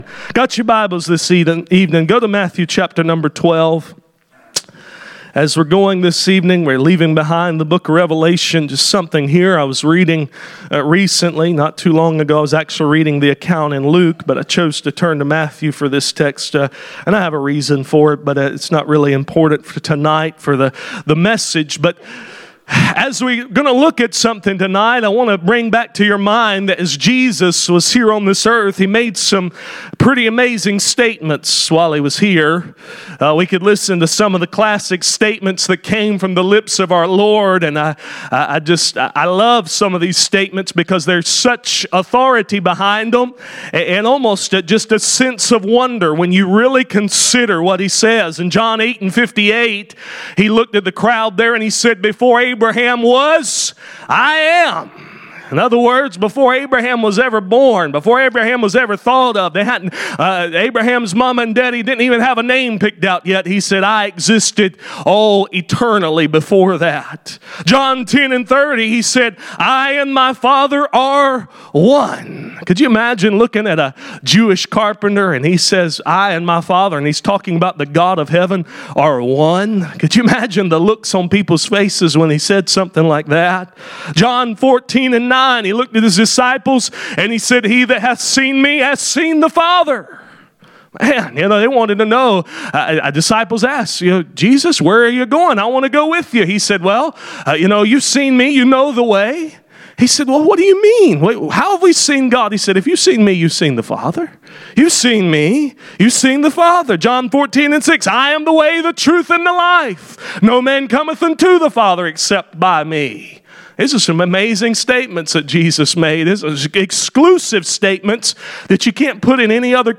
Matthew 12:42 Service Type: Sunday Evening « The Lukewarm legacy of Laoeicea A borrowed donkey